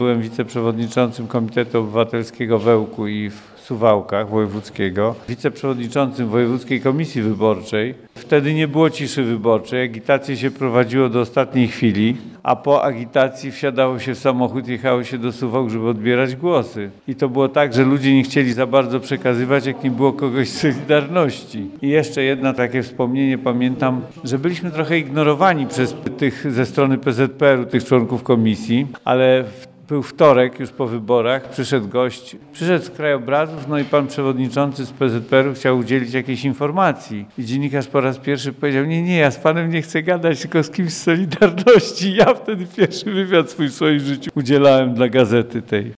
Obecny na otwarciu wystawy wspominał tamte czasy.